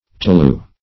Tolu \To*lu"\, n.